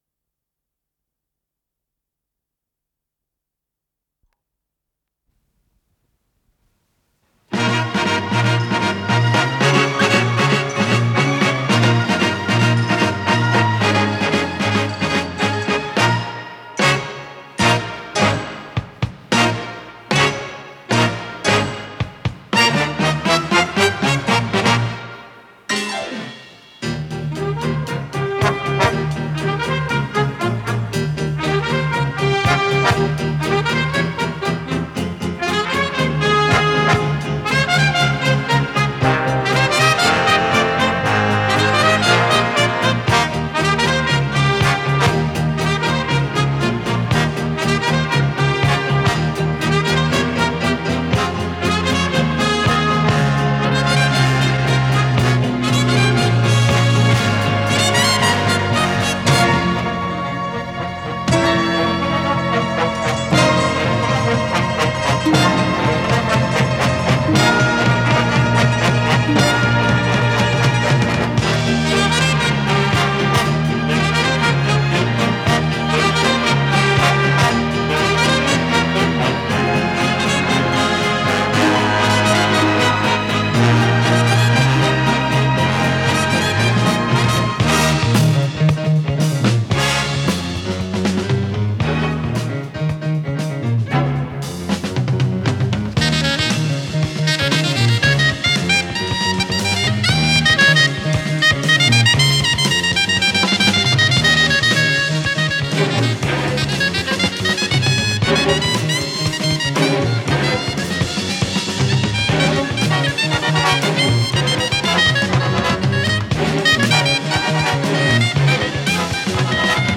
сопрано-саксофон
ВариантДубль моно